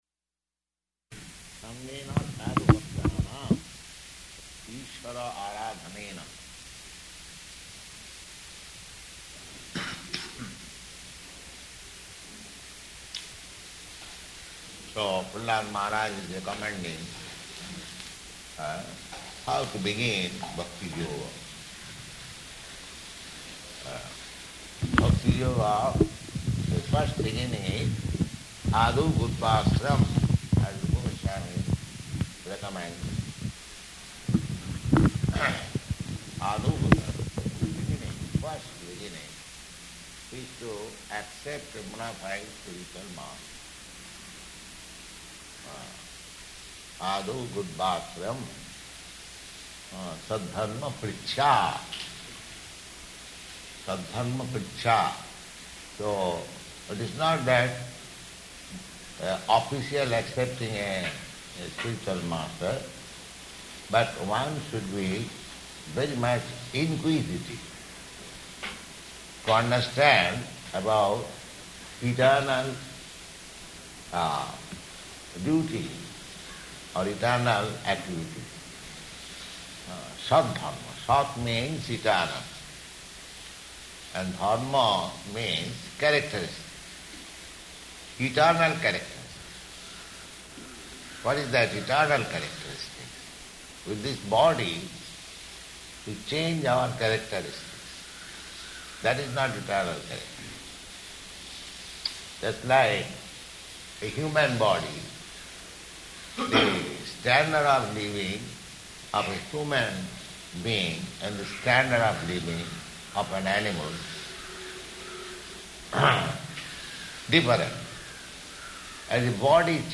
Location: Mombasa